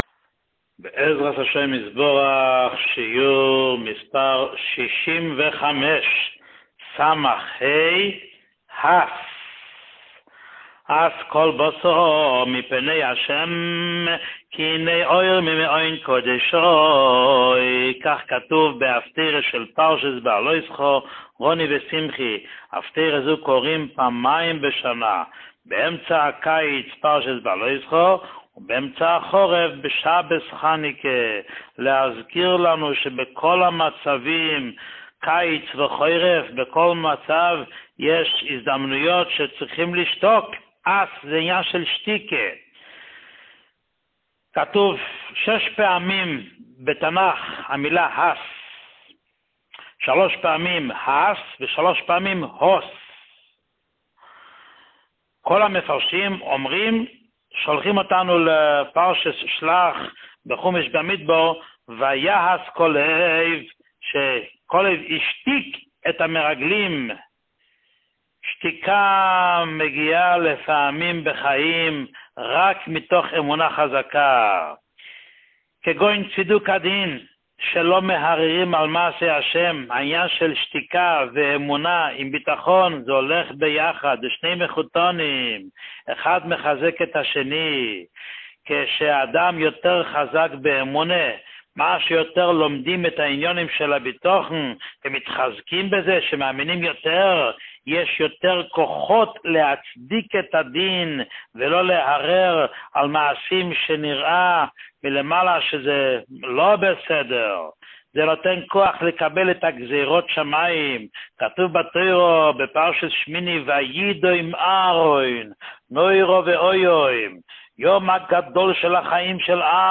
שיעור 65